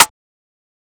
Rim (Paranoia).wav